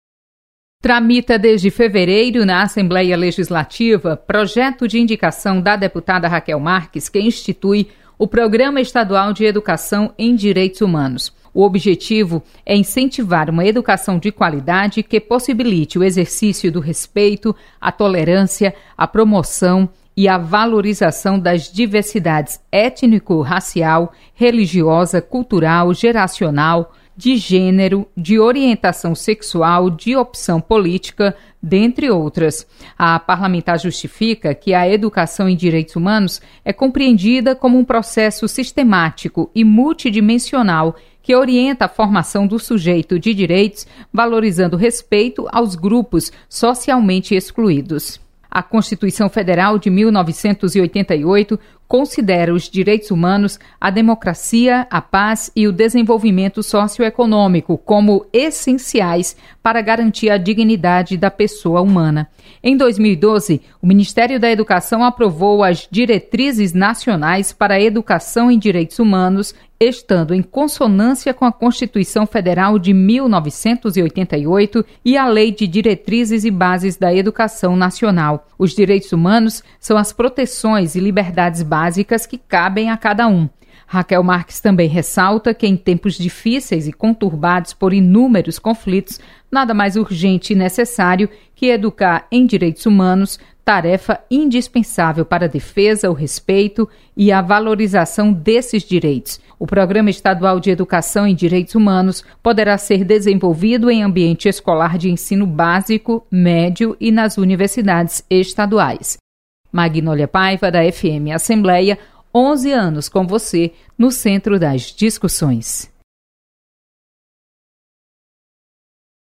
Projeto cria programa estadual de educação em Direitos Humanos. Repórter